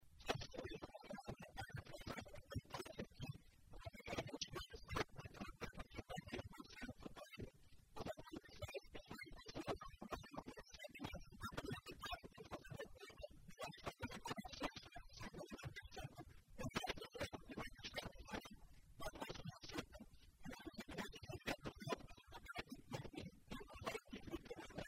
O vice-prefeito de Boa Vista do Cadeado, José Fracaro, ressalta que somente com a soja, na recente safra, houve perda de aproximadamente 195 milhões de reais no município, visto a falta de chuva.
SONORA-JOSE-FRACARO19.05.mp3